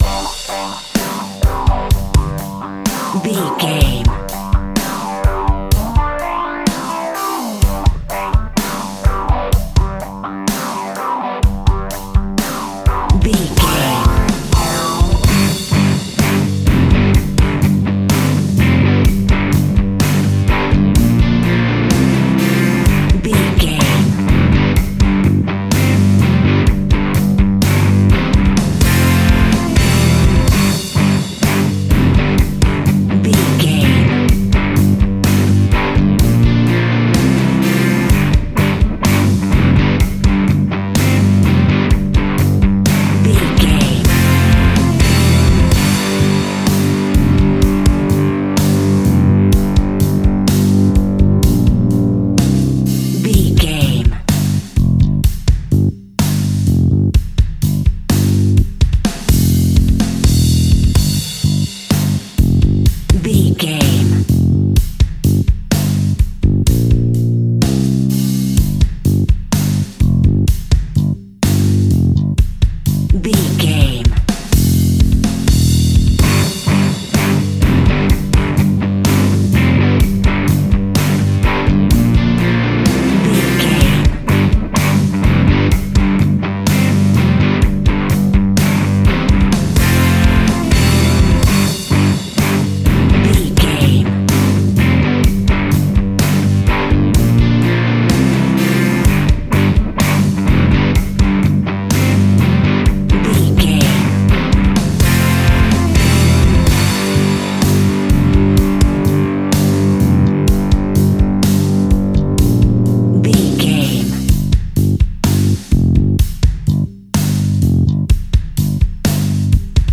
Ionian/Major
energetic
driving
happy
bright
electric guitar
bass guitar
drums
hard rock
blues rock
distortion
rock instrumentals
heavy drums
distorted guitars
hammond organ